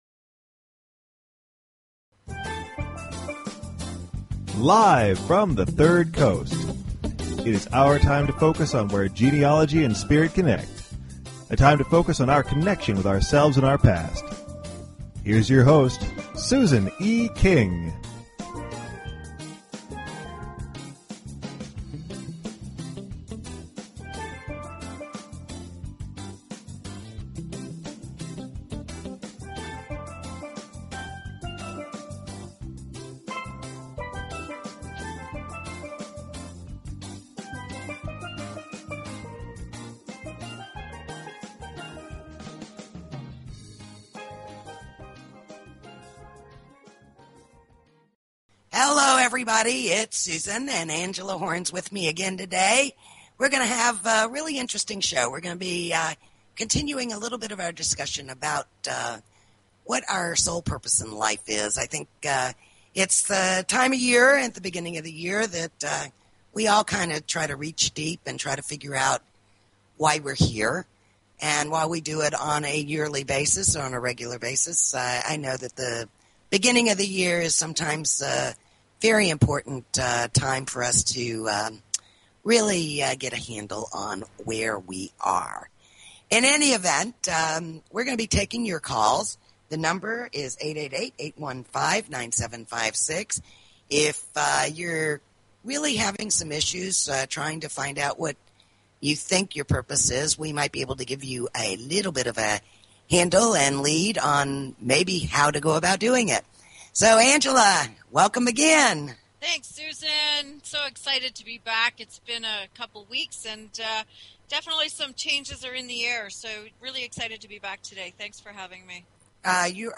Talk Show Episode, Audio Podcast, Where_Genealogy_and_Spirit_Connect and Courtesy of BBS Radio on , show guests , about , categorized as